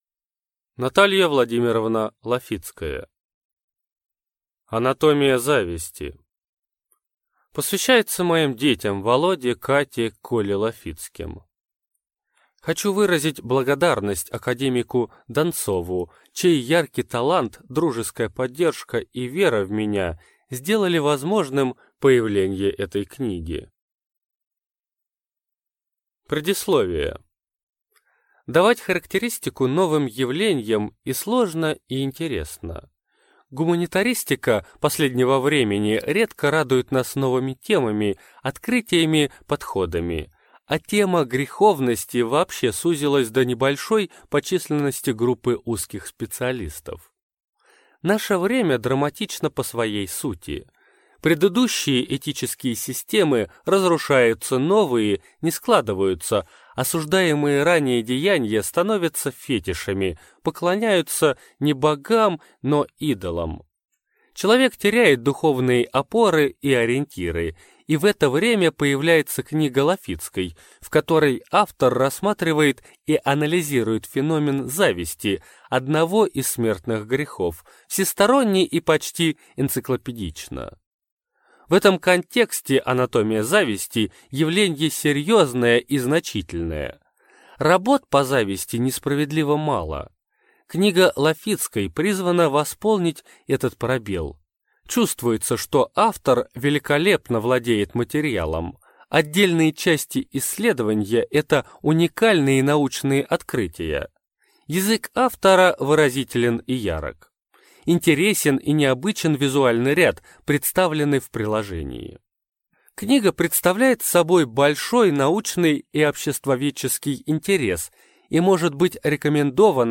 Аудиокнига Анатомия зависти | Библиотека аудиокниг
Прослушать и бесплатно скачать фрагмент аудиокниги